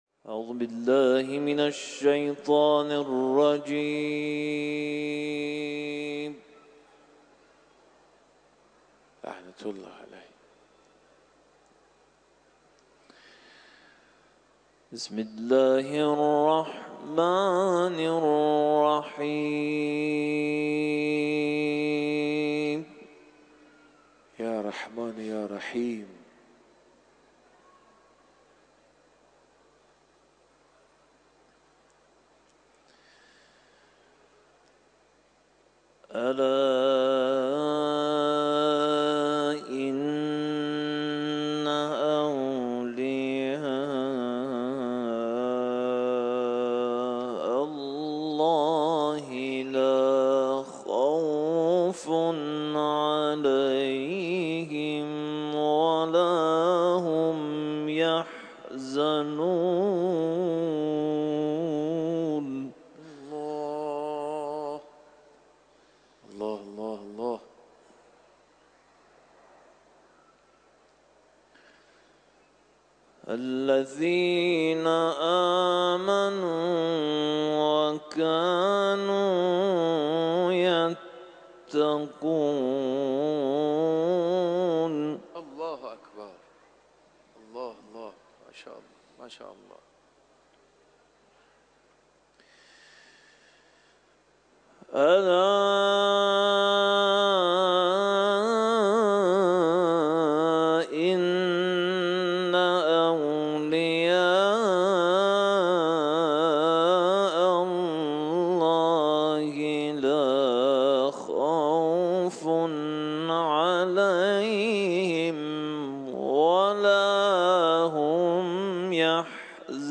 در حرم امام رضا(ع)
تلاوت